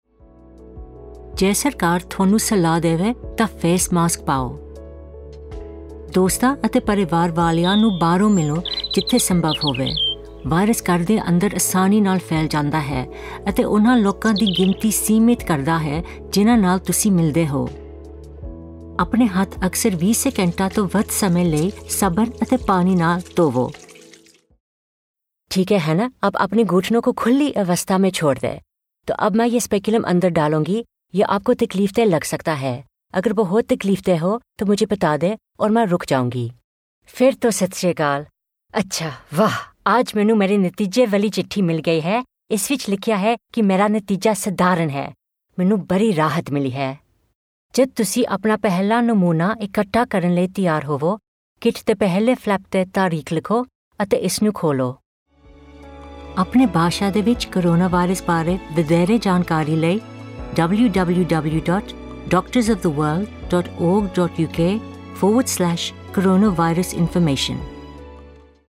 RP + British Indian (Hindi, Punjabi). Friendly, warm, reassuring, youthful, approachable, natural | Rhubarb Voices